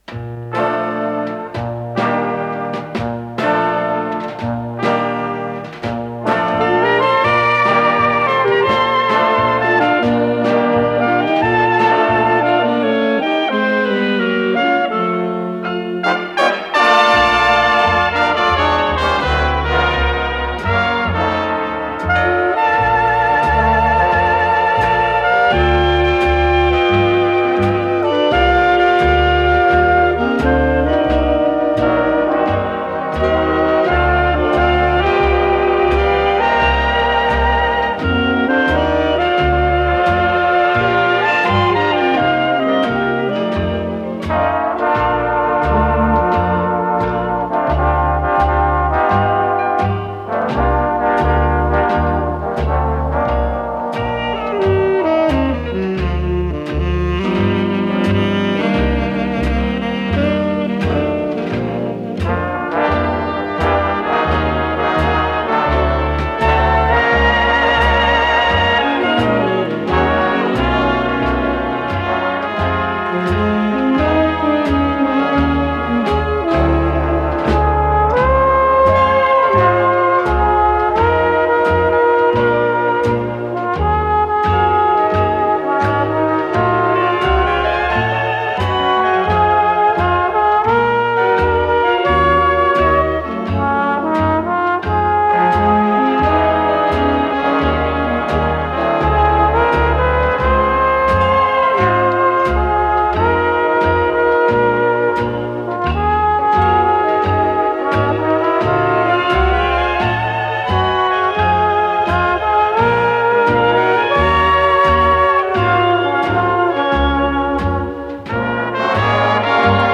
тромбон